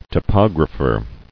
[to·pog·ra·pher]